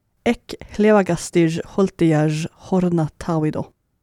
ملف تاريخ الملف وصلات معلومات الصورة (ميتا) DR-12-urnordiska.opus  (Ogg Opus ملف صوت، الطول 4٫0ث، 140كيلوبيت لكل ثانية) وصف قصير ⧼wm-license-information-description⧽ DR-12-urnordiska.opus Svenska: Uppläsning av inskriften på Gallehushornet på (DR 12) urnordiska. English: A reading of the inscription on the Gallehus horn (DR 12) in proto-Norse.